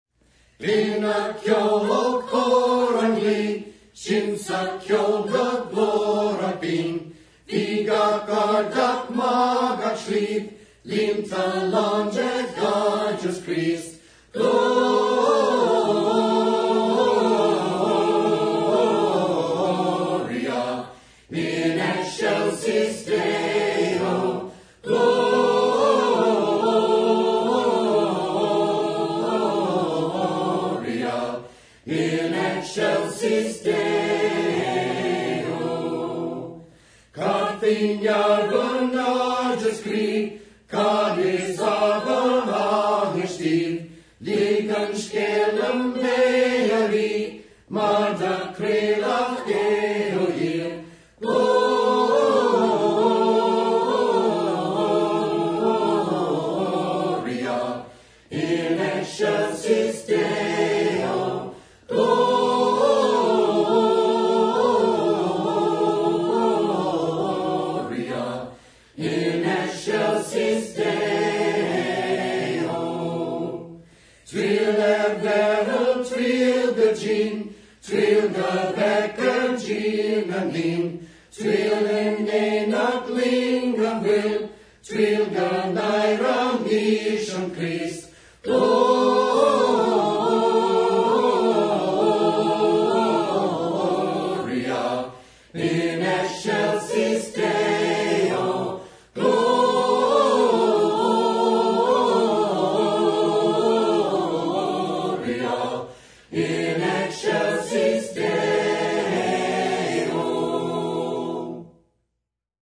I loved the Xmas carol in Gaelic - very pretty language when sung.
excelsis(choir).mp3